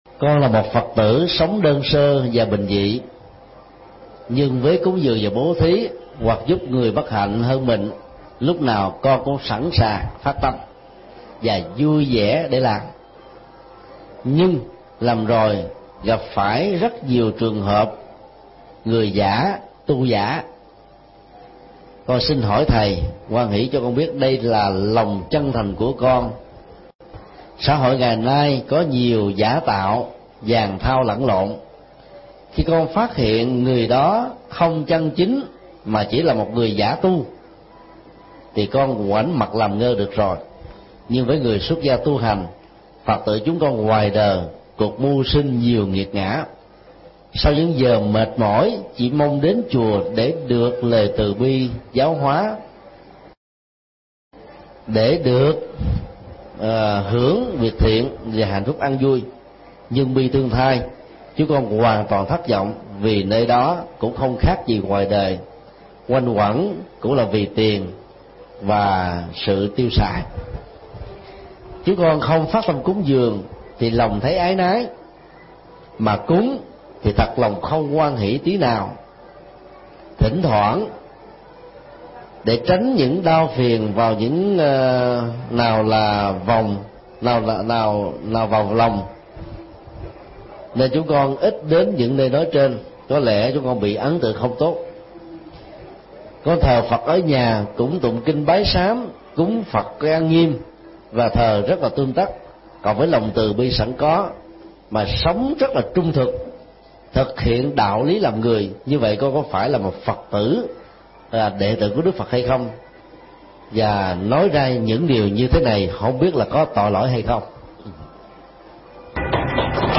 Vấn đáp: Ứng xử với người không chân tu